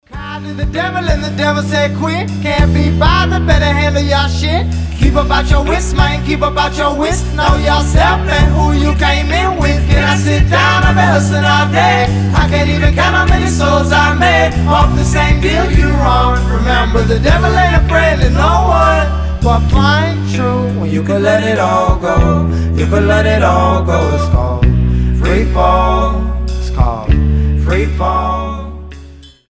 indie pop
alternative